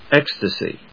音節ec・sta・sy 発音記号・読み方
/ékstəsi(米国英語), ˈekstʌsi:(英国英語)/